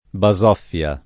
basoffia [ ba @0 ff L a ] → bazzoffia